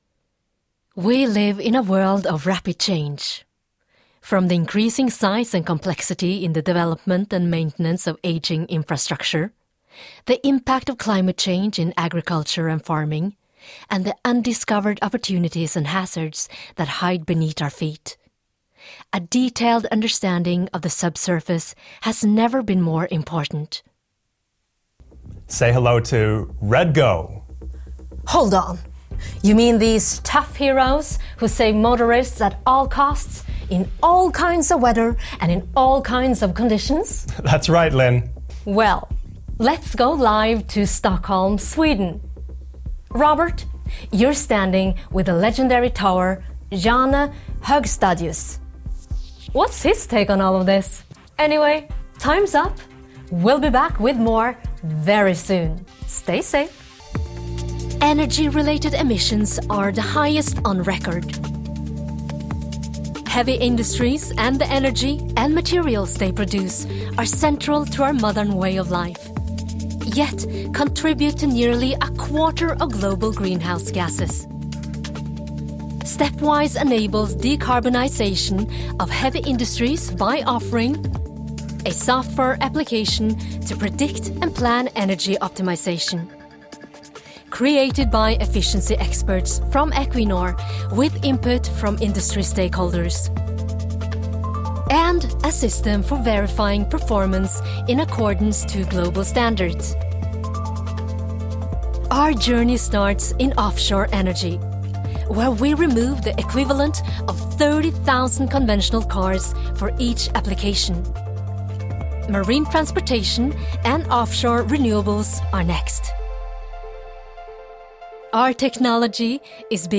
Female
Friendly, Confident, Character, Corporate, Energetic, Natural, Warm, Engaging
commercial_Norwegian.mp3
Microphone: SM Pro Audio MC01